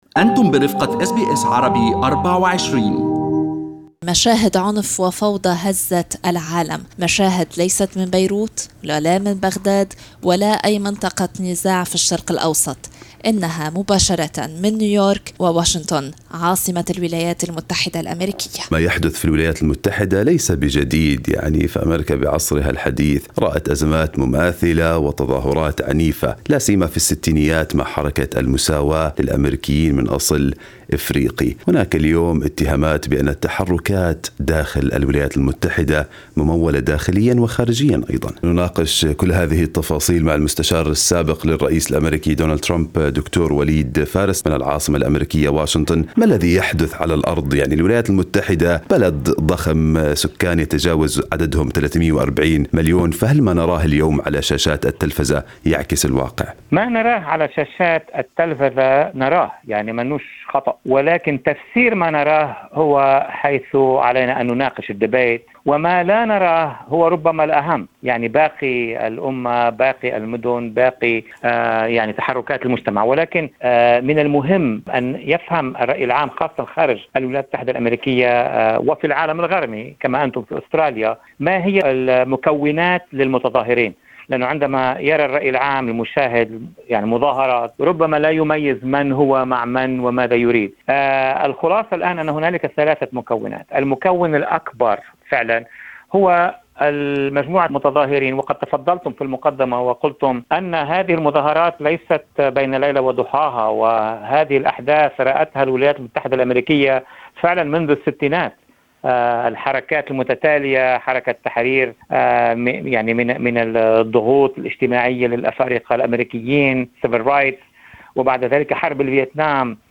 لقاء خاص مع المستشار السابق في حملة الرئيس الأميركي دونالد ترامب الدكتور وليد فارس يتحدث فيه عن أعمال العنف والاحتجاجات التي تشهدها الولايات المتحدة الأميركية.